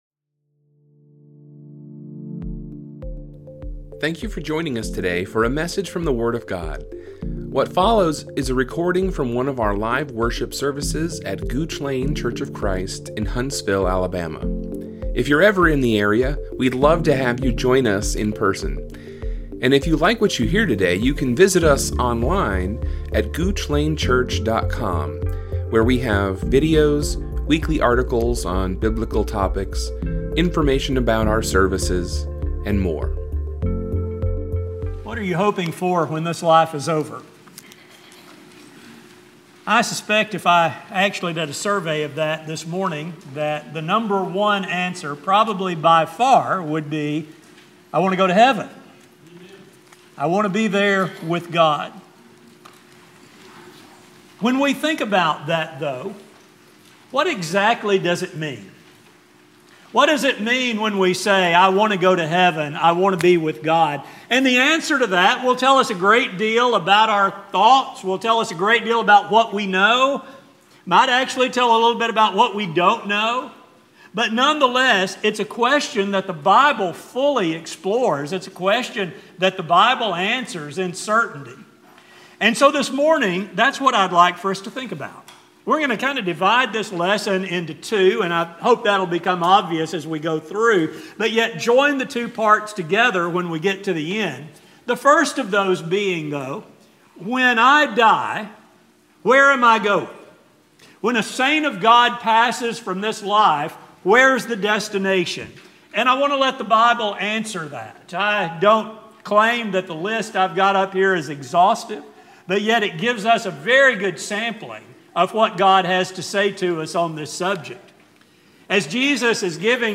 This sermon will focus on Jesus’ view of eternal life, as well as what it means to know and be known by Him.